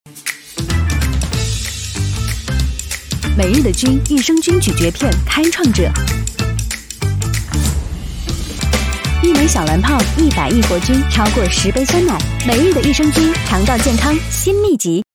女15-【广告】每日的菌
女15--广告-每日的菌.mp3